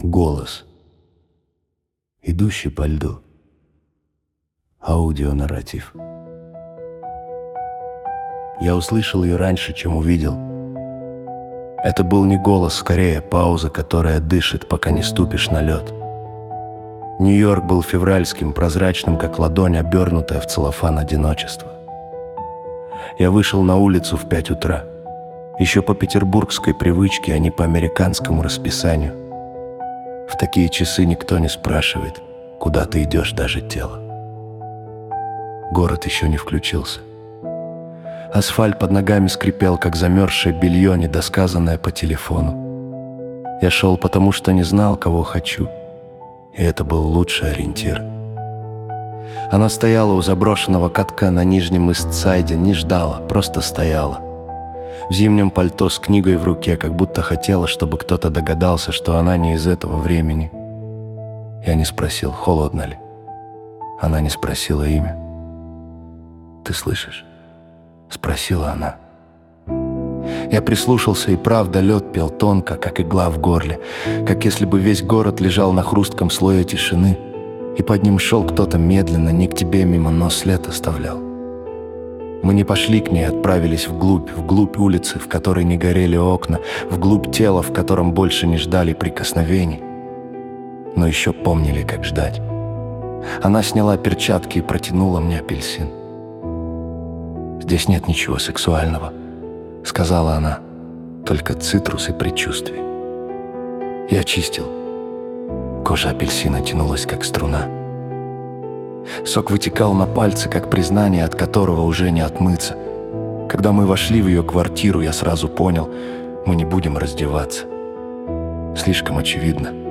Аудио-нарратив